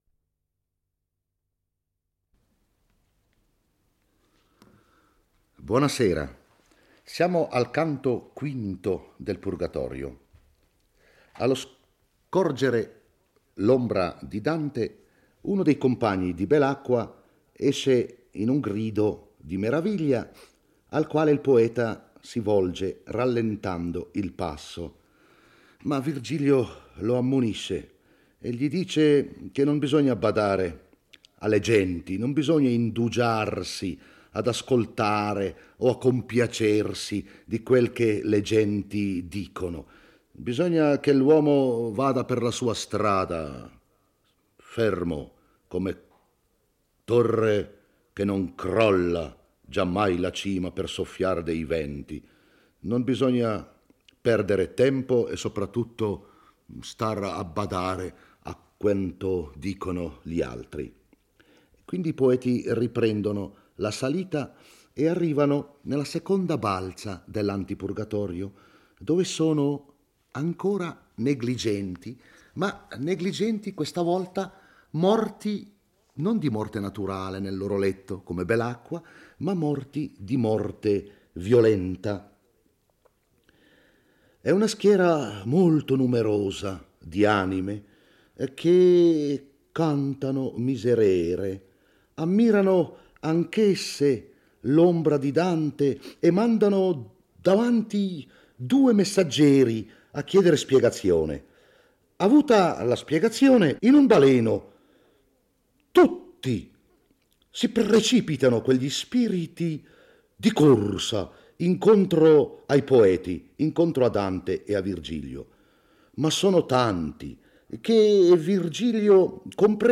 legge e commenta il V canto del Purgatorio. Durante la salita, Dante e Virgilio incontrano una schiera di anime che cantano il Miserere: sono coloro che morirono di morte violenta, i quali pregano Dante di fermarsi a riconoscere qualcuno perché, tornato sulla terra, possa portare il loro ricordo ai vivi.